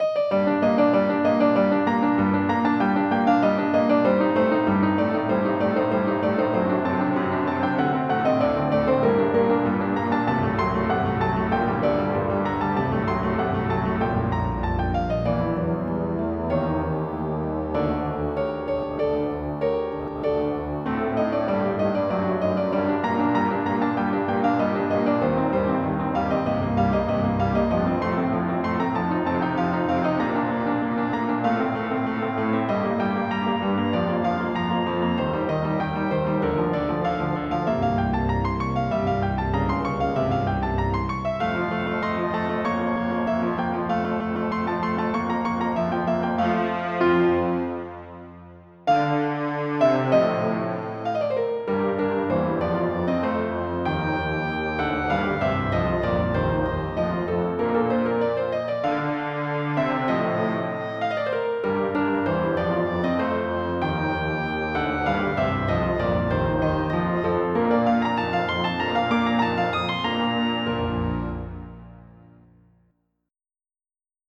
MIDI Music File
Pattern I Type General MIDI